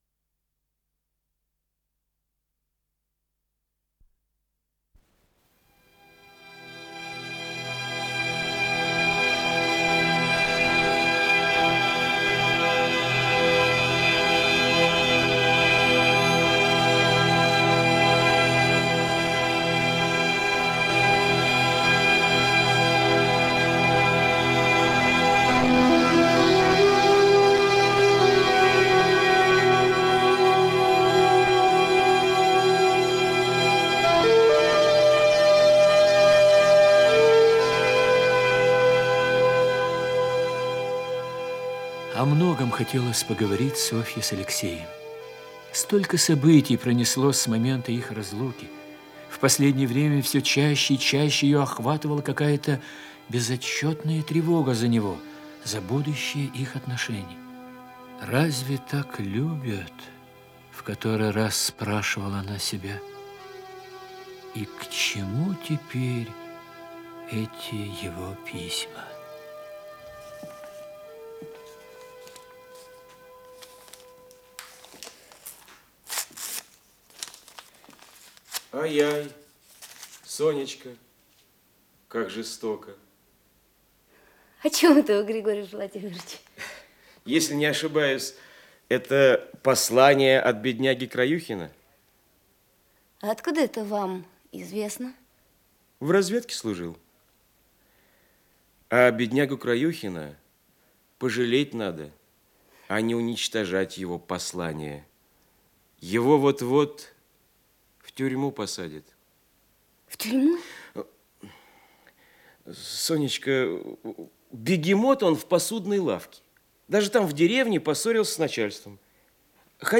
Исполнитель: Артисты Государственного академического Малого театра СССР
Радиокомпозиция спектакля